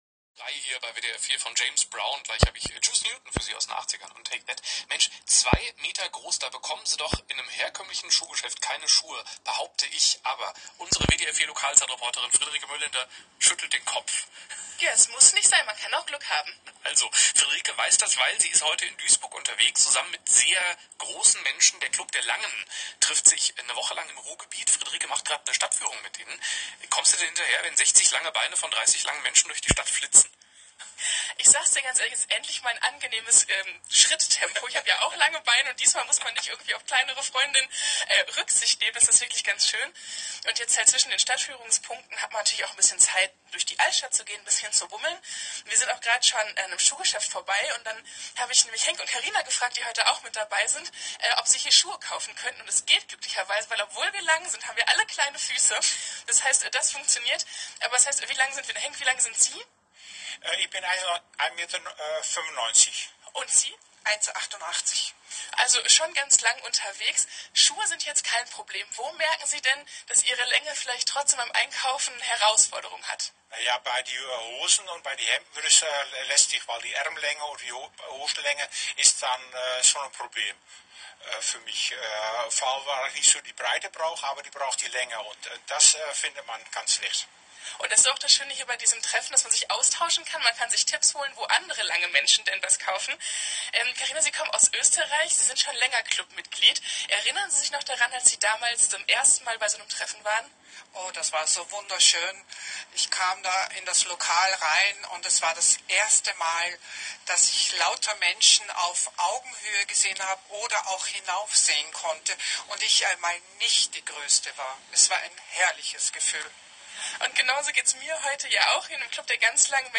26.05.2025 WDR4 radio report interview Guided tour of the old town